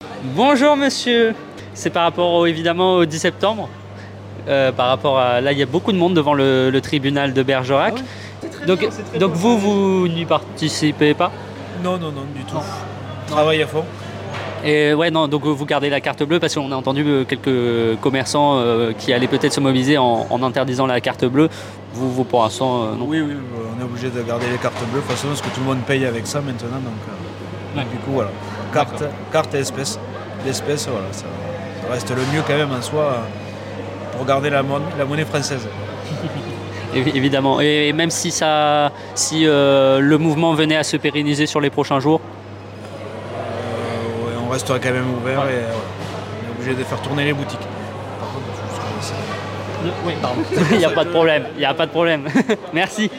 LES INTERVIEWS HAPPY RADIO – MANIFESTATION BERGERAC – COMMERÇANT